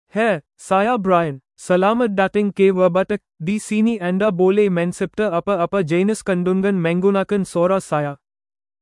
BrianMale Malayalam AI voice
Brian is a male AI voice for Malayalam (India).
Voice sample
Listen to Brian's male Malayalam voice.
Male